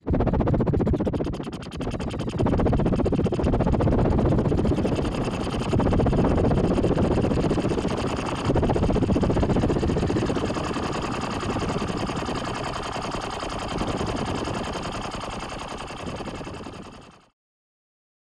The sounds are mostly space-age, weird naughty noises, and buzzy things -- cutting edge for 1976.